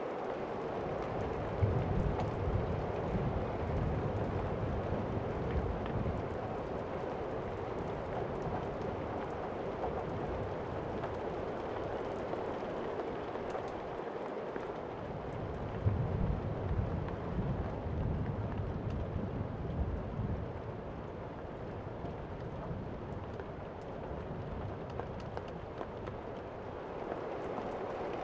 thunder.ogg